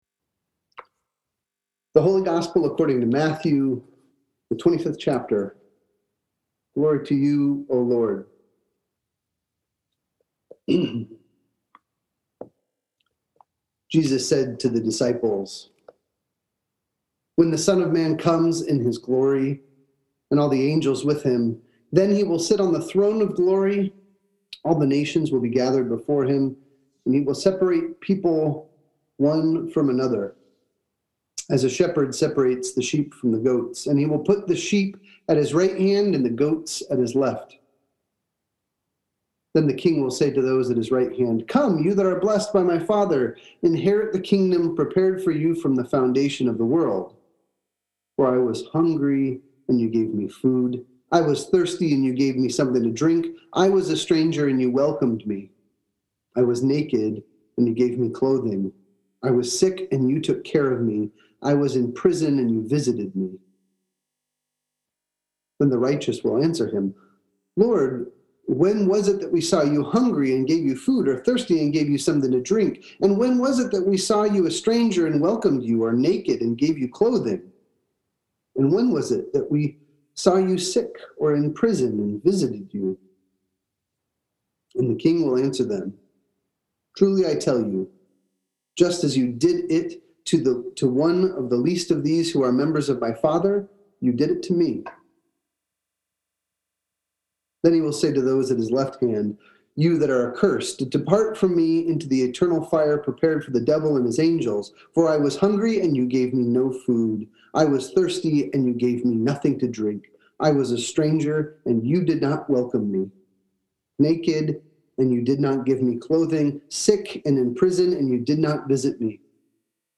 Sermons | Shepherd of the Valley Lutheran Church